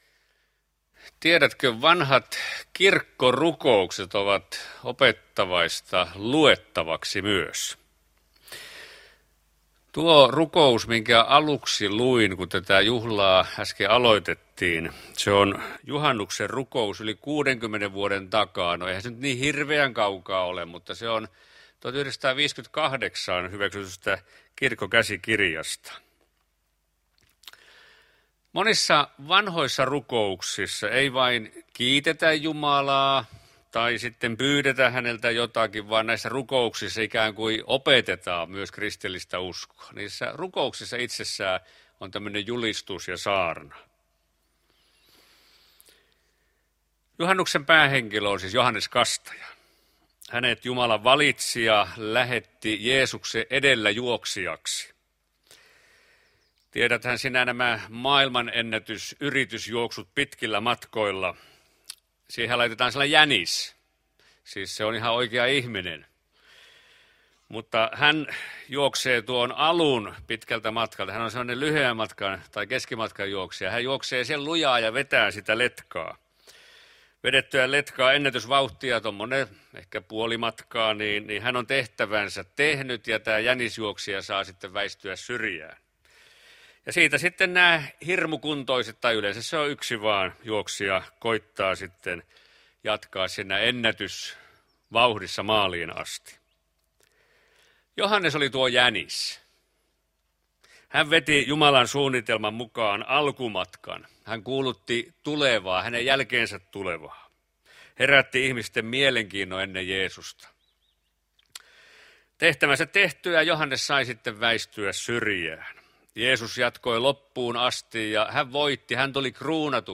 Lapuan Kauhajärvi